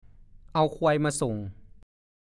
ฐานข้อมูลพจนานุกรมภาษาโคราช